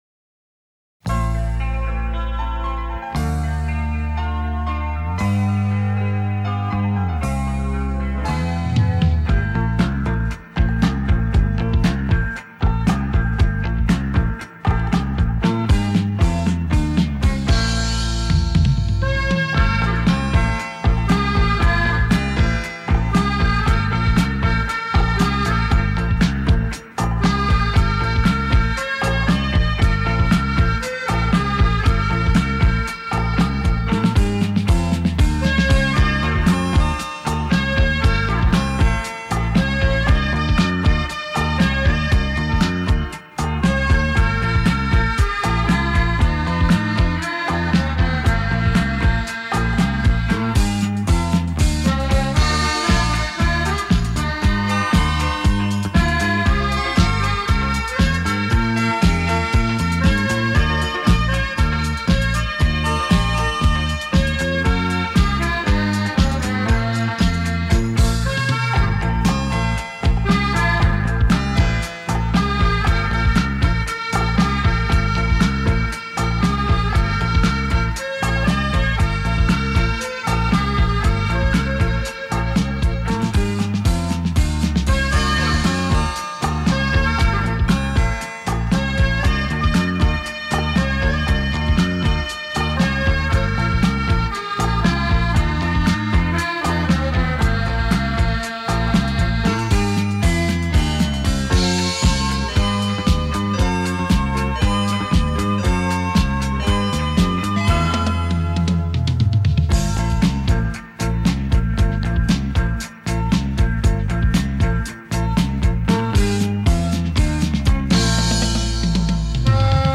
TAPE转APE